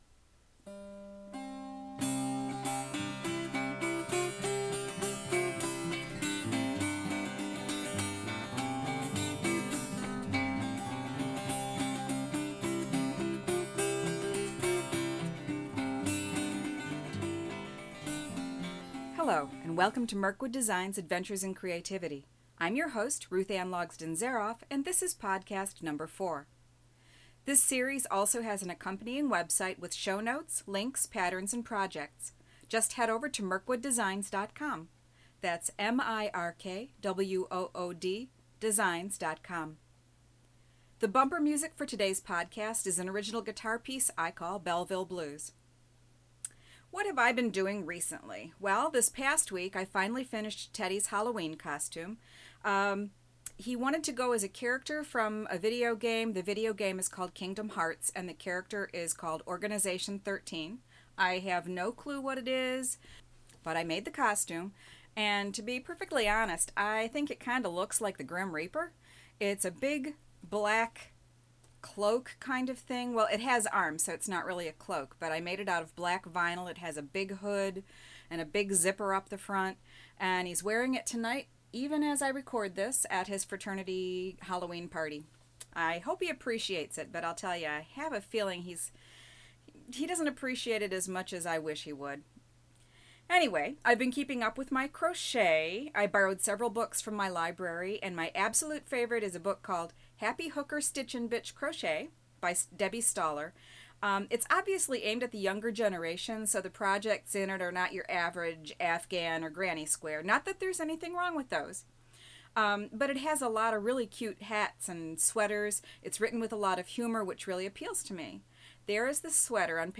INTRODUCTION Today's bumper music is an original guitar piece I call Belleville Blues .